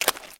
STEPS Swamp, Walk 24.wav